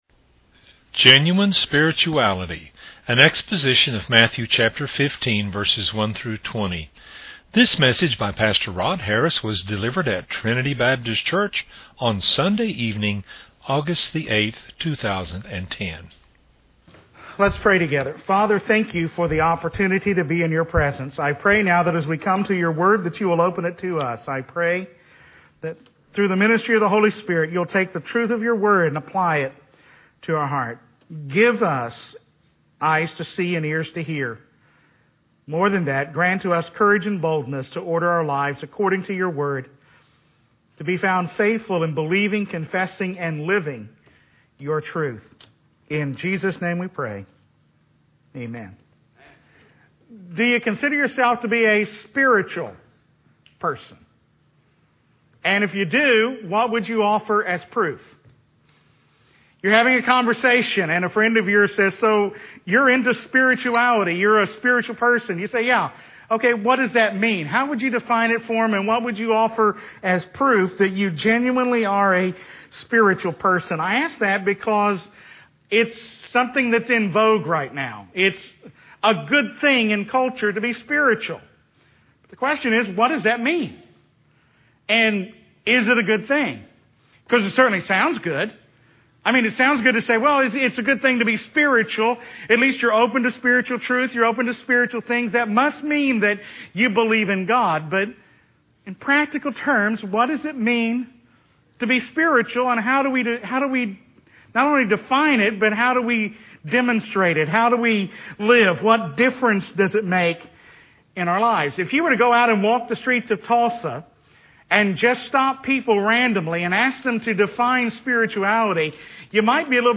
was delivered at Trinity Baptist Church on Sunday evening, August 8, 2010.